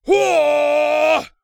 ZS长声1.wav
ZS长声1.wav 0:00.00 0:01.44 ZS长声1.wav WAV · 124 KB · 單聲道 (1ch) 下载文件 本站所有音效均采用 CC0 授权 ，可免费用于商业与个人项目，无需署名。
人声采集素材/男3战士型/ZS长声1.wav